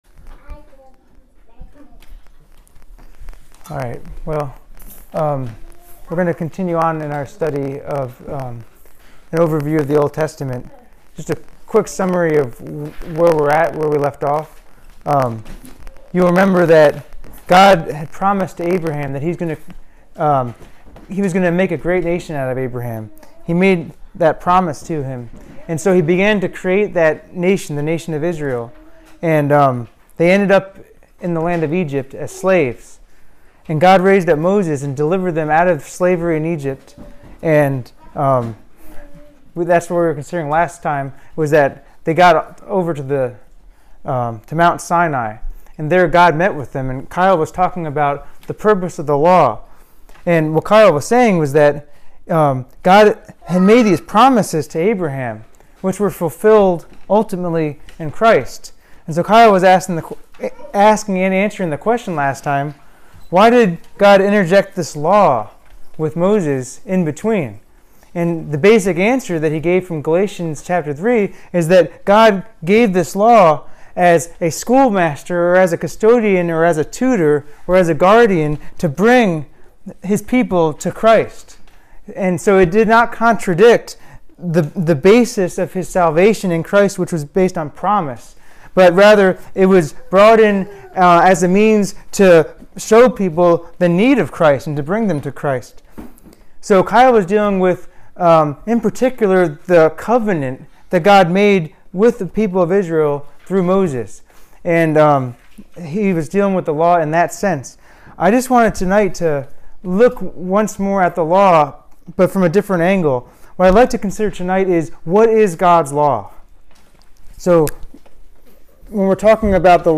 Audio –> Live broadcast Aug 27, 2016, 7-08 PM